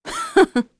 Seria-Vox-Laugh_kr.wav